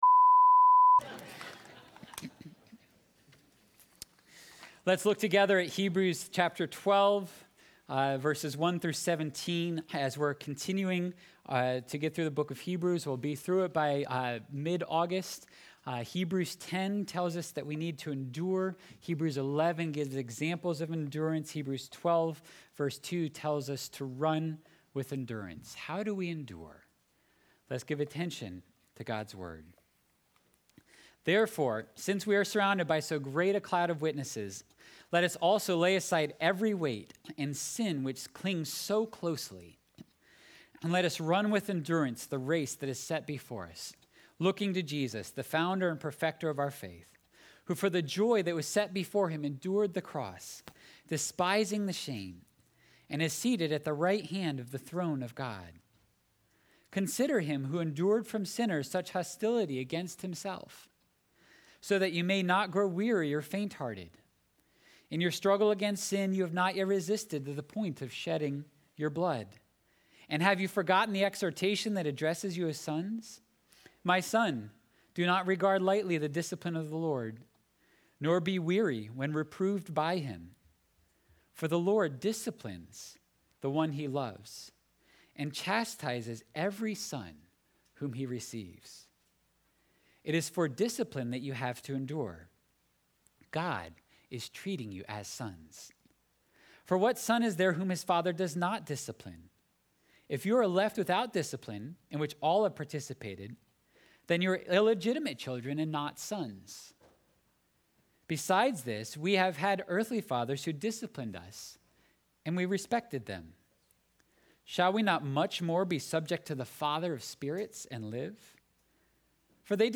Sunday Worship – July 18, 2021 – The Lord Disciplines Those He Loves
7.18.21-sermon-audio.mp3